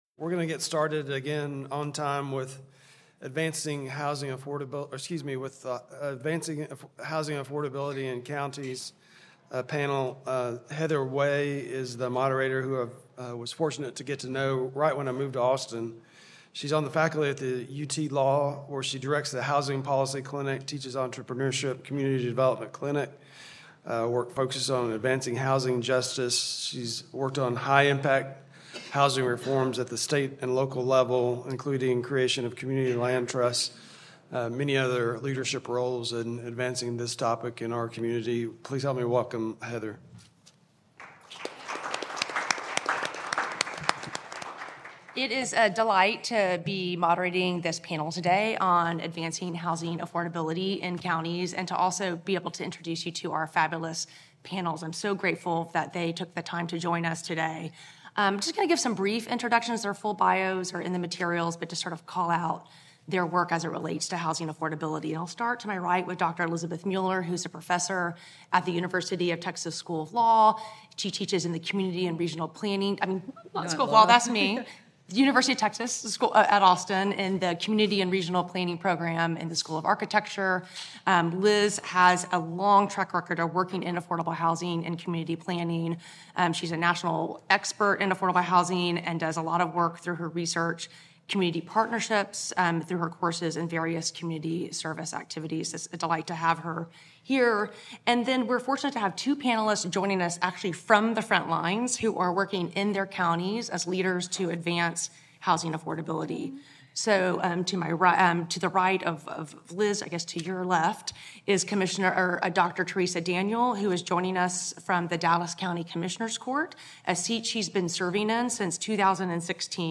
Counties across the state are struggling with housing affordability, with population surges in unincorporated areas and a growing mismatch between housing needs and supply. Hear from county leaders and housing experts on tools that counties are using to address local affordable housing needs, along with opportunities for expanding counties' toolboxes.
Originally presented: Apr 2024 Land Use Conference